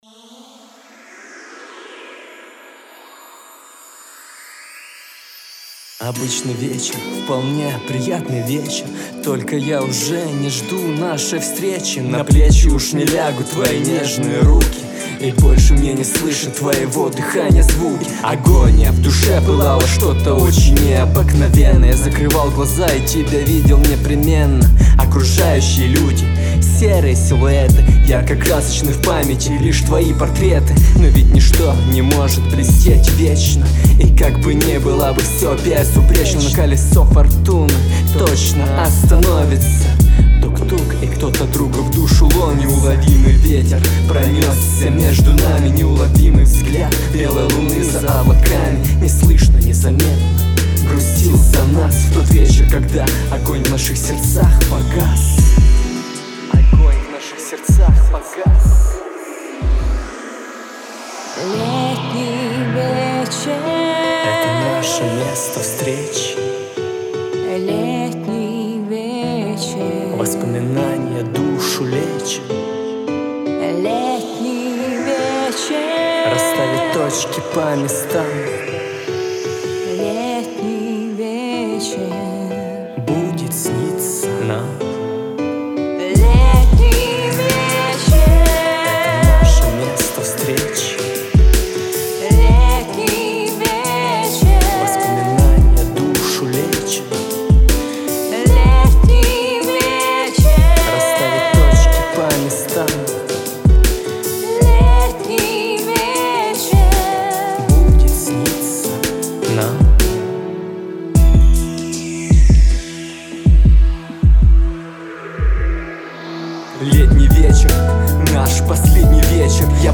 Романтическая композиция о ностальгии встреч.